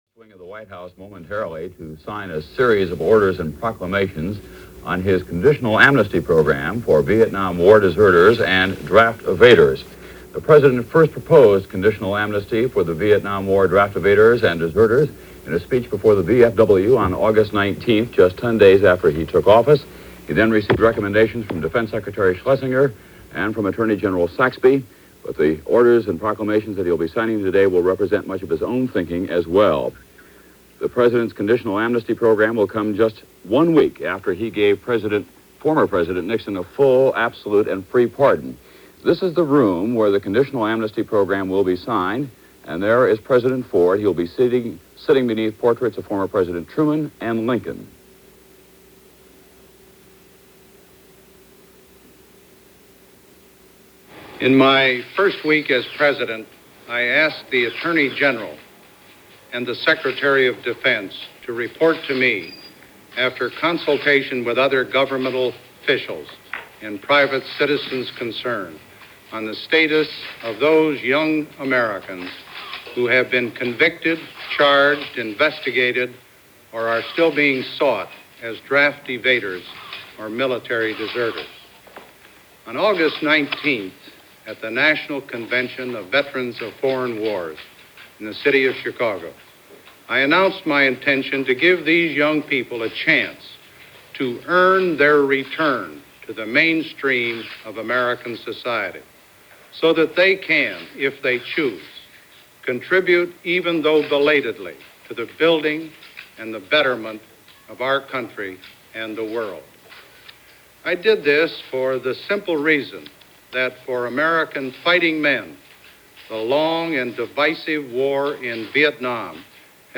A short announcement, but a profound one, made from the Oval Office and broadcast throughout the world in 1974.